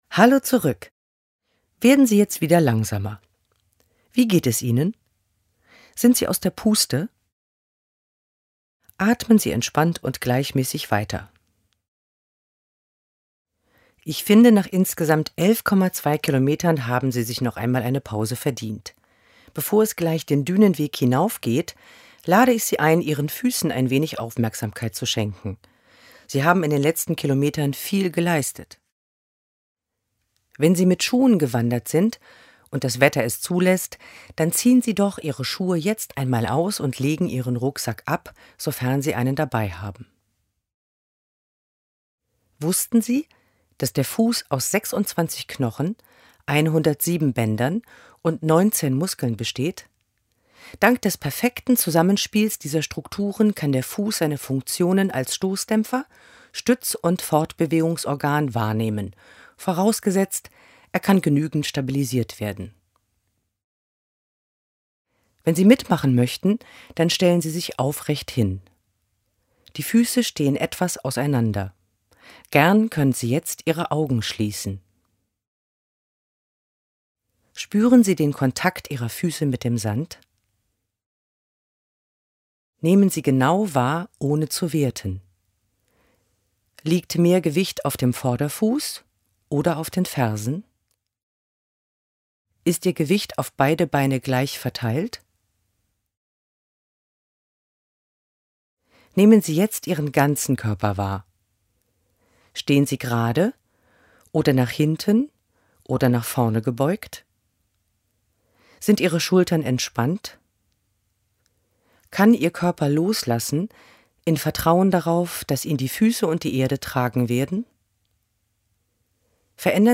Audioguide